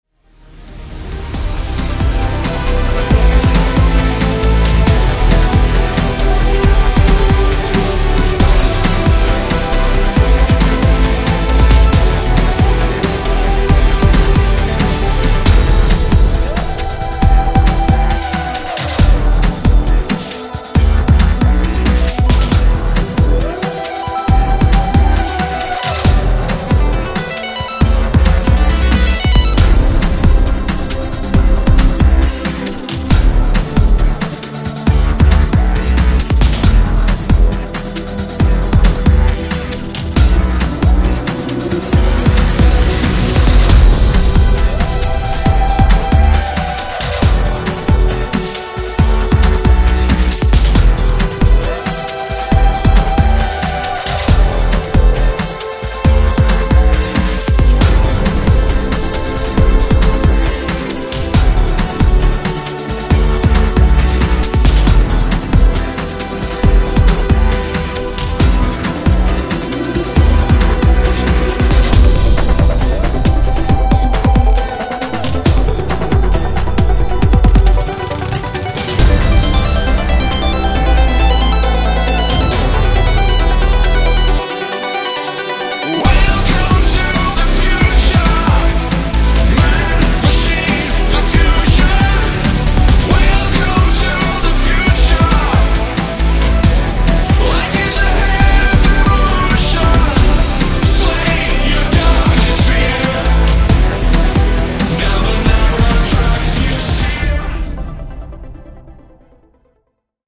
• Качество: 128, Stereo
OST трэк из игры версия 2